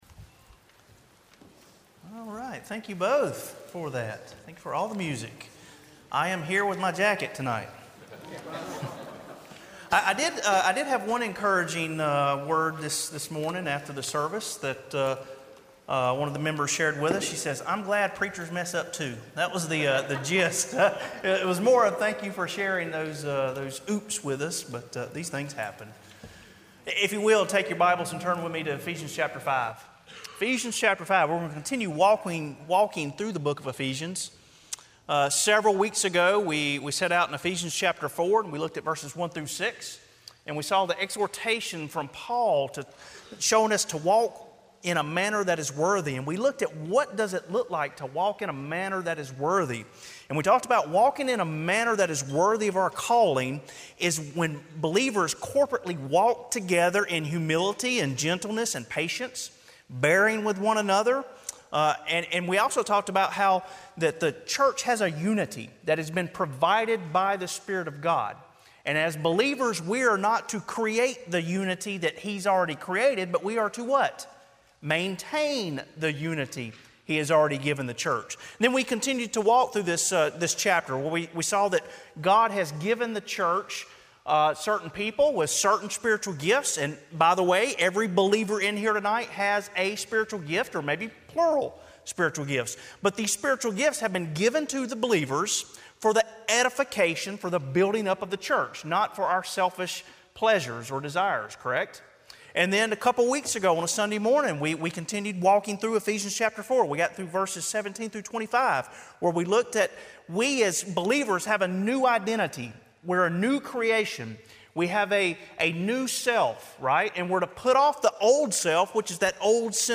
Sermon Audios/Videos - Tar Landing Baptist Church
Evening WorshipEphesians 5:3-14